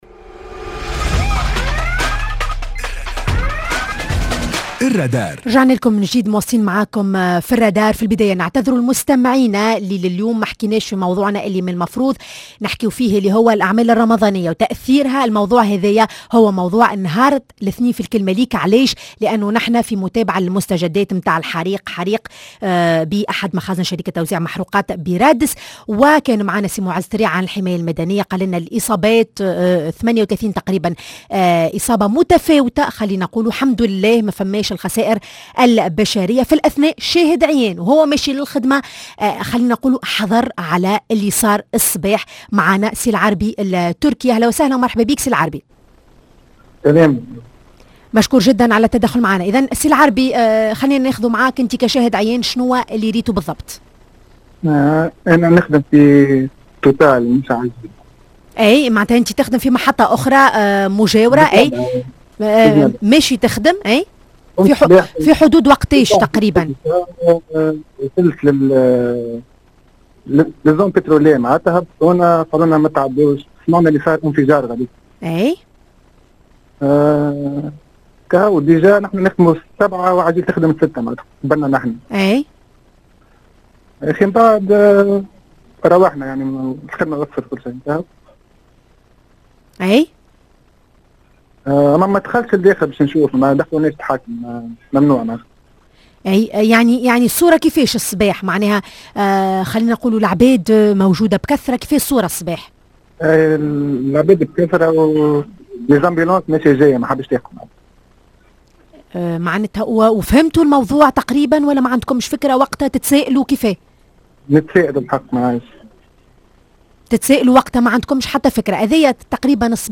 حادث اندلاع حريق بمستودع للغاز في رادس معنا شاهد عيان